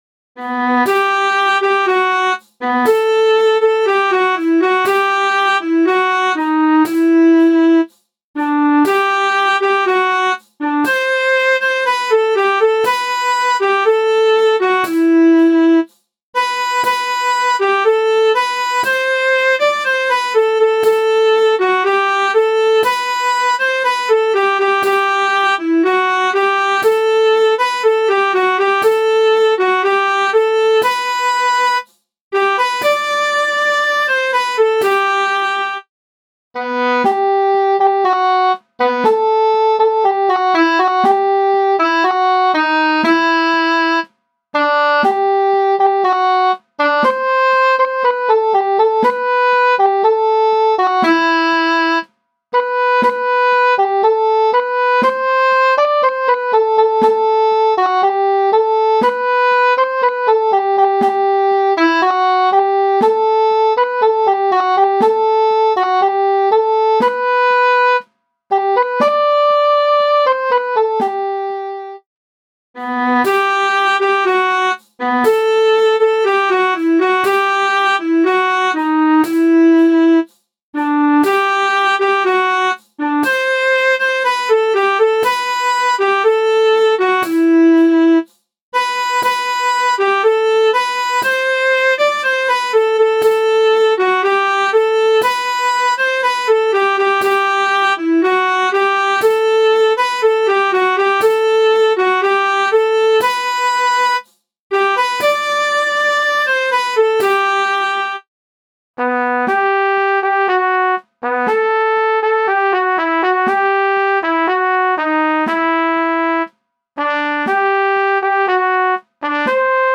MIDI von 2014 [7.192 KB] - mp3